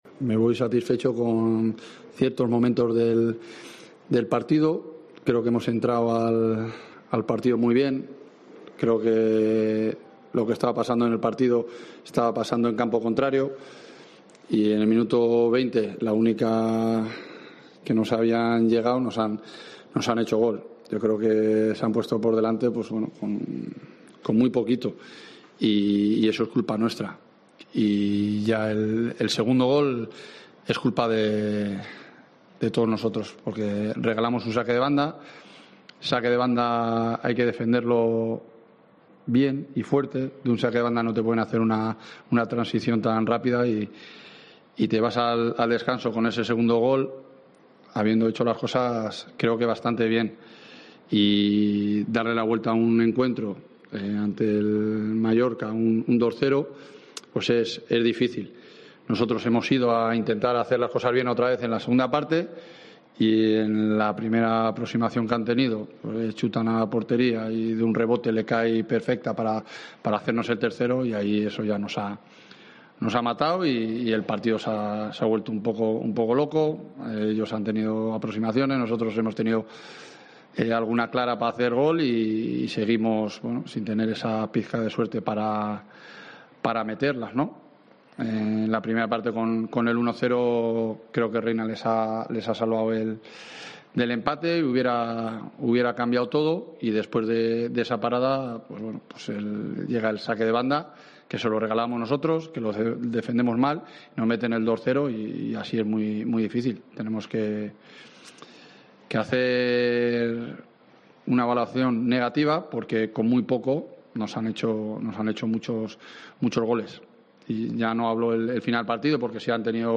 AUDIO: Escucha aquí las declaraciones de Jon Pérez Bolo, entrenador de la Deportiva, y Luis García Plaza, míster del Mallorca